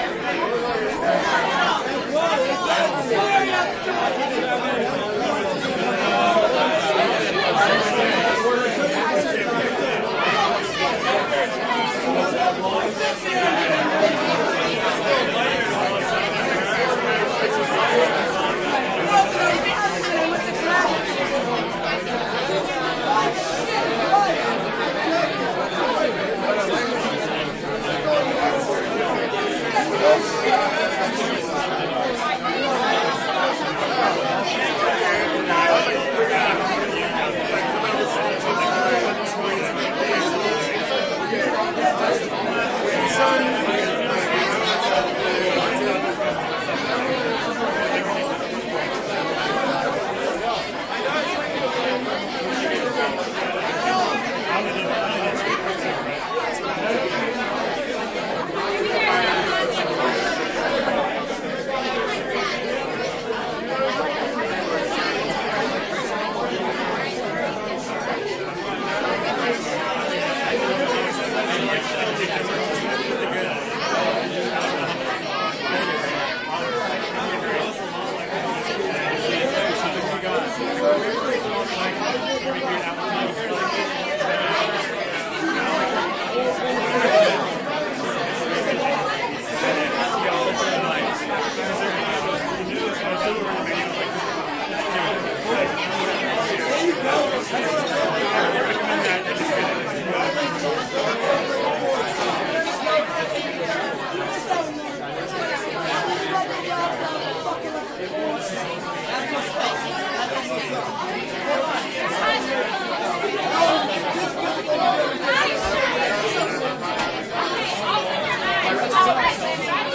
酒吧音响
描述：在现场办公室录制的，这是一个嘈杂的德克萨斯潜水酒吧，充满了喝啤酒的人和吵闹的朋友。
标签： 社会 潜水酒吧 啤酒 现场办公 人群 酒吧
声道立体声